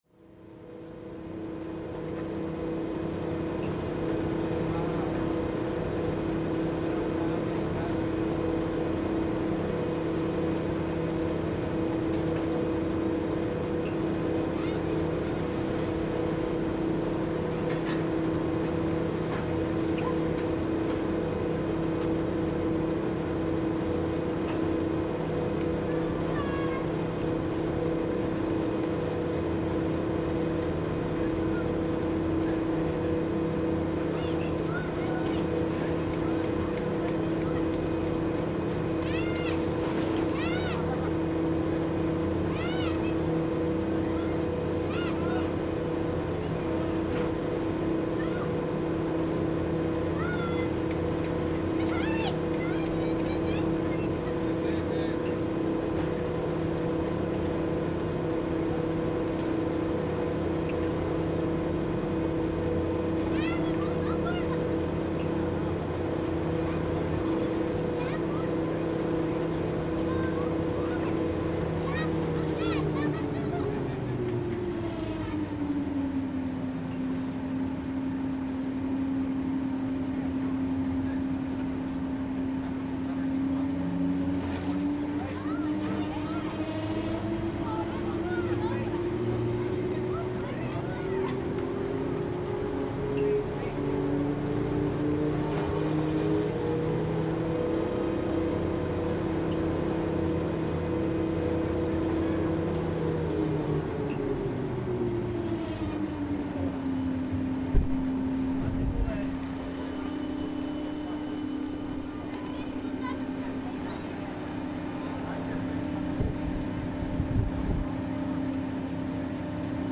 Ski lift at Passo Rolle
Recording of a ski lift mechanism from the bottom of the ski slope at Passo Rolle in the Italian Dolomites.